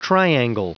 Prononciation du mot triangle en anglais (fichier audio)
Prononciation du mot : triangle